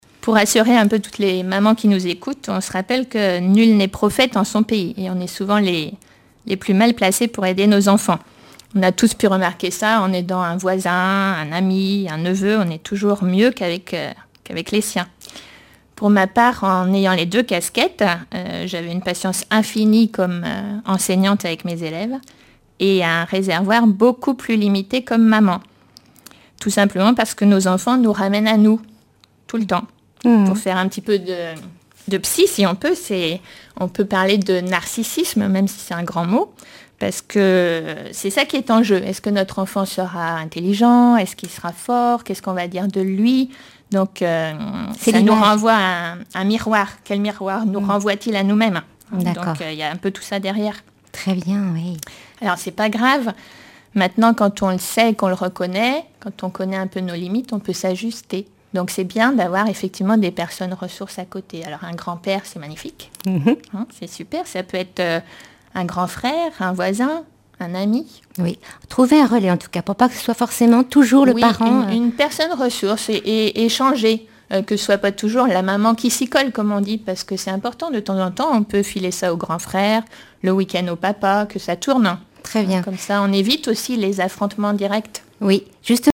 Emissions de la radio RCF Vendée
témoignages sur l'accompagnement scolaire